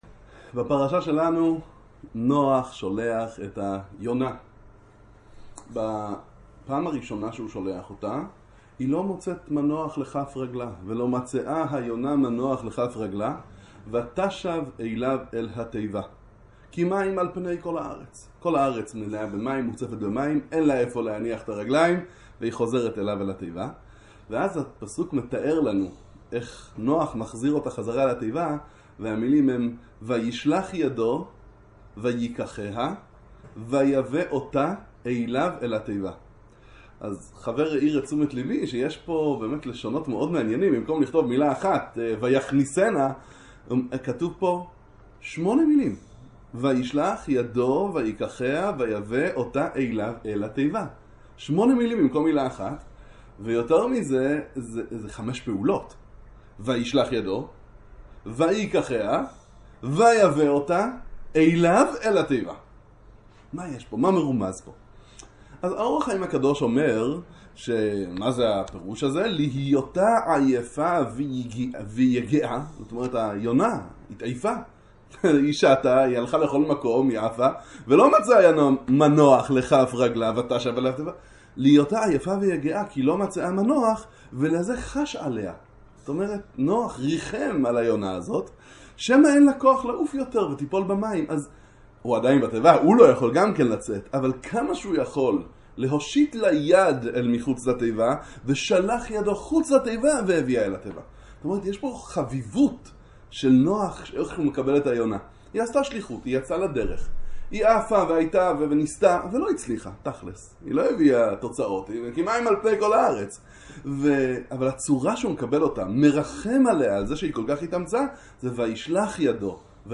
ללמדך… היחס לשליח שלא הצליח… עם ישראל, והצורה שנח מקבל את היונה המאוכזבת – דבר תורה קצר לפרשת נח